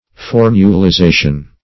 Search Result for " formulization" : The Collaborative International Dictionary of English v.0.48: Formulization \For`mu*li*za"tion\, n. The act or process of reducing to a formula; the state of being formulized.